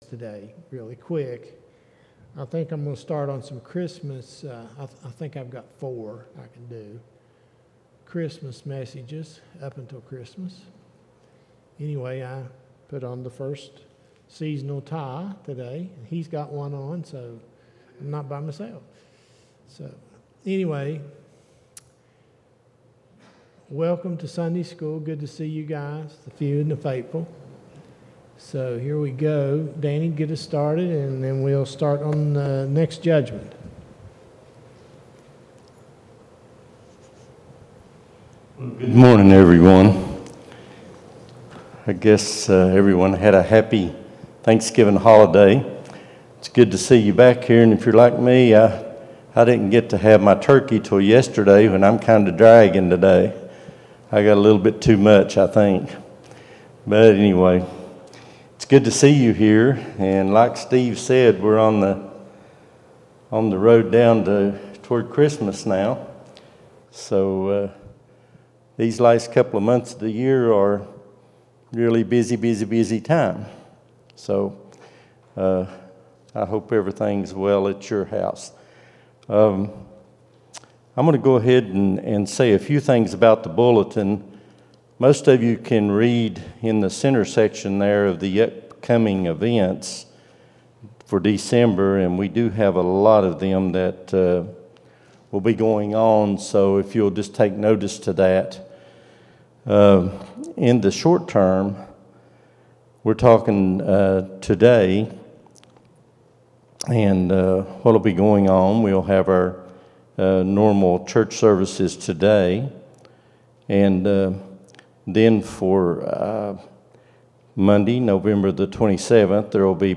11-26-23 Sunday School | Buffalo Ridge Baptist Church